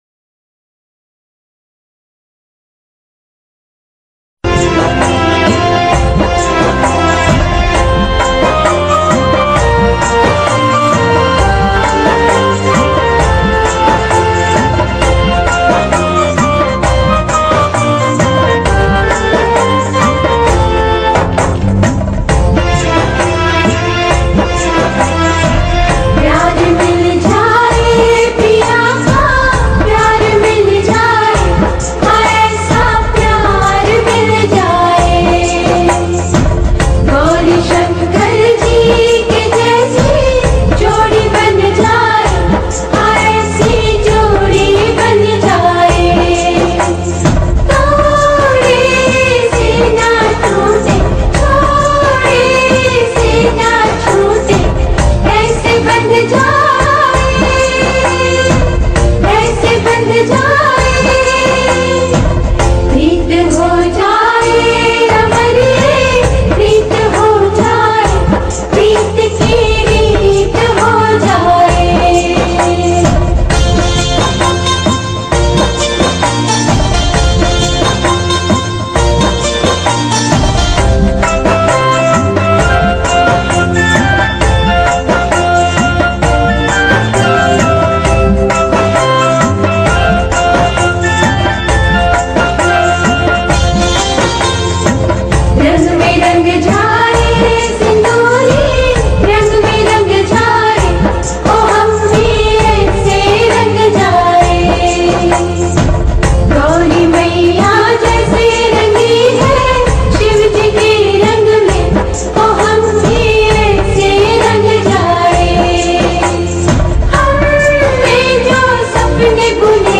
Releted Files Of Bhakti Gana